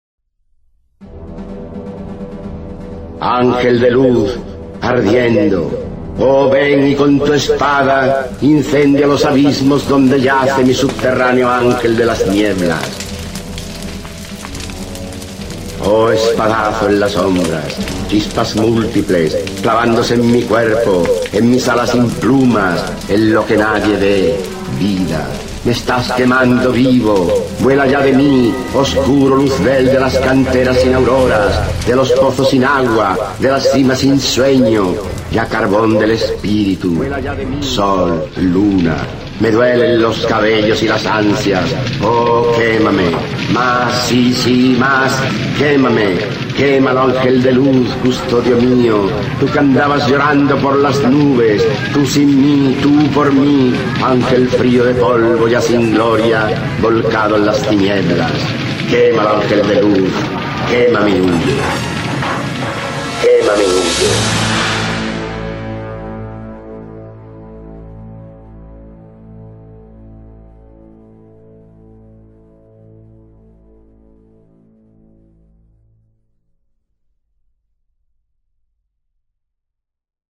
Archivo de sonido con la voz del escritor español Rafael Alberti, quien recita su poema “Los dos ángeles" (Sobre los ángeles, 1927-1928)